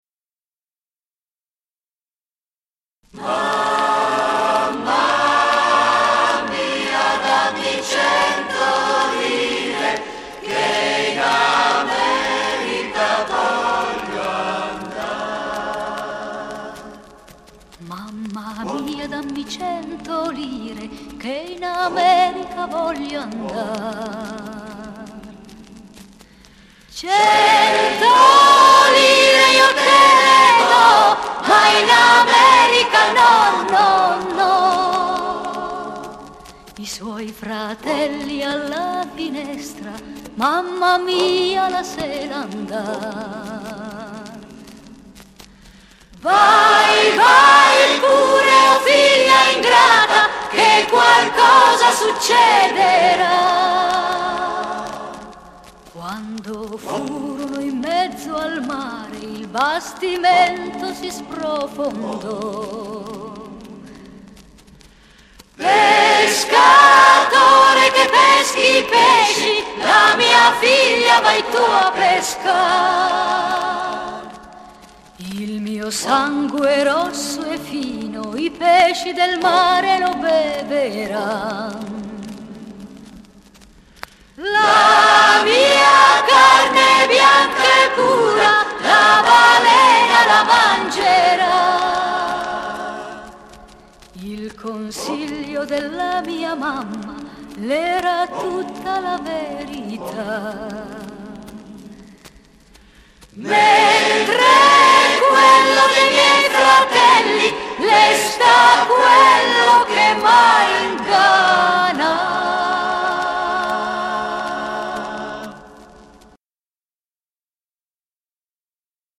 motivo popolare